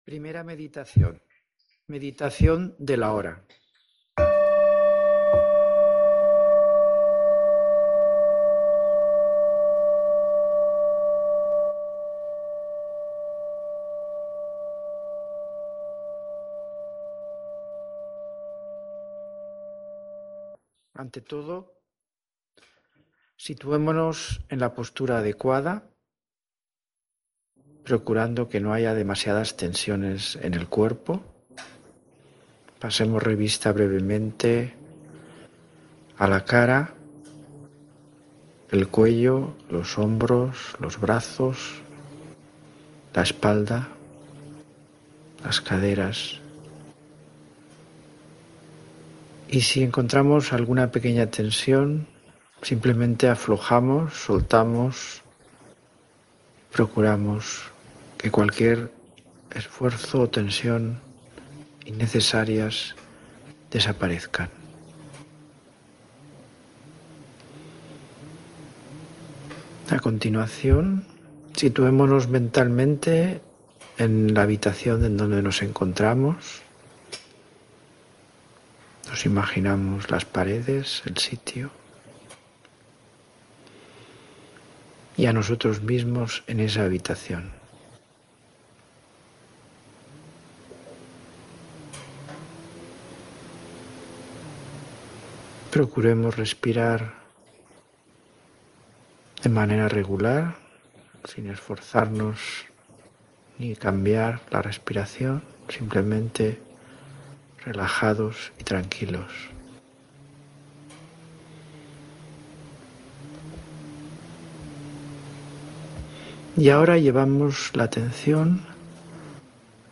Meditaciones guiadas en audio